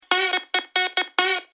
Категория: Рингтон на SMS